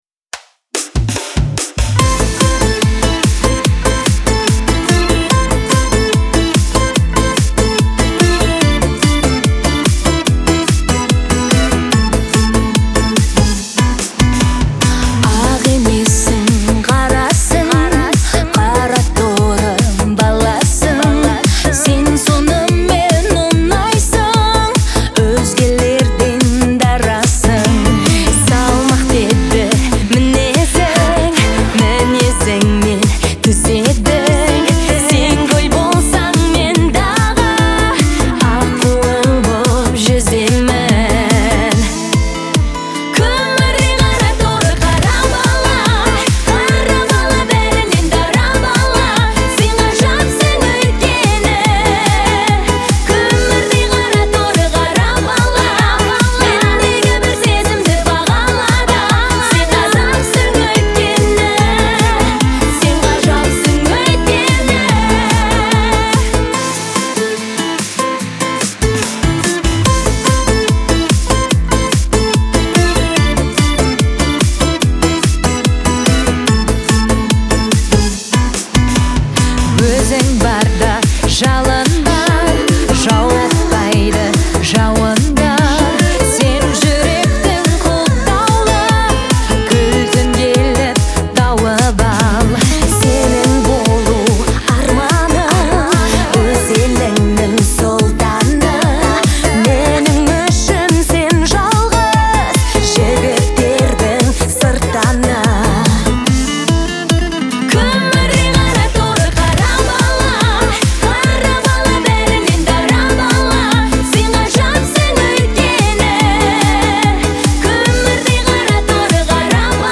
это яркий пример казахского поп-рока